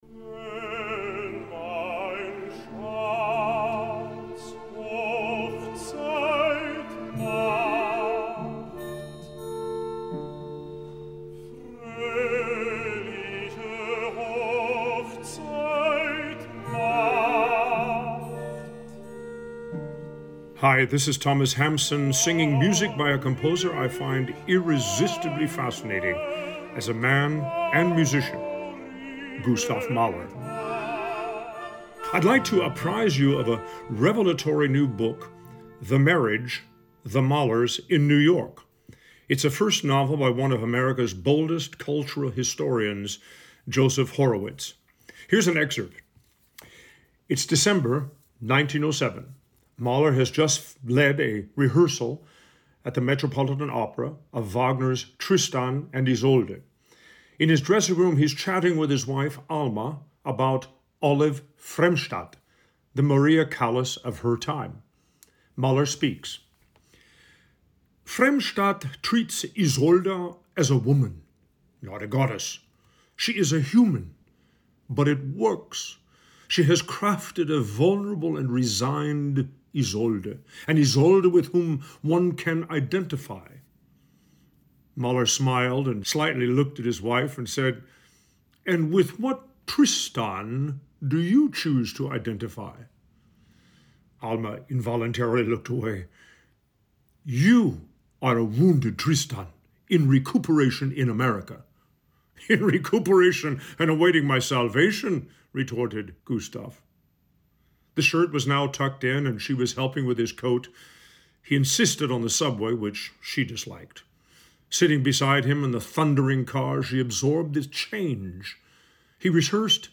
Clips of Thomas Hampson reading from the book: